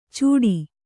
♪ cūḍi